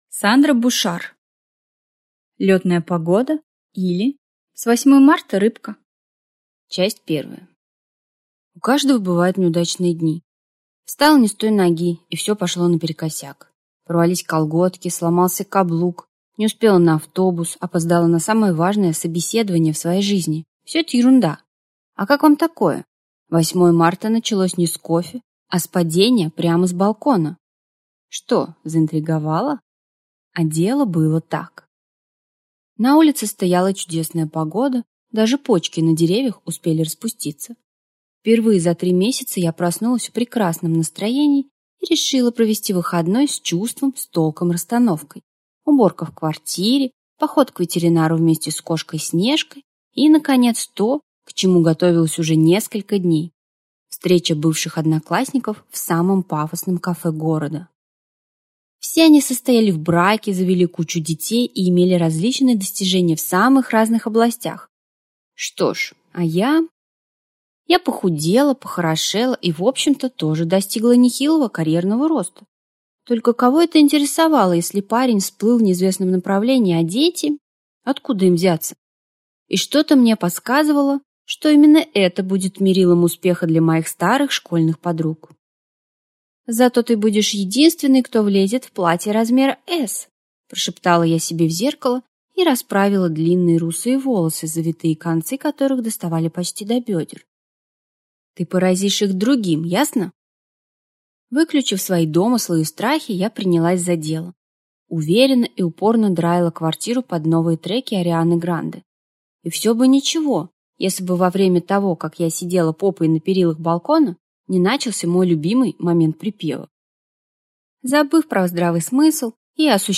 Аудиокнига Лётная погода, или С 8 марта, рыбка! | Библиотека аудиокниг